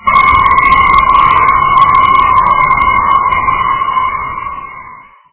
screm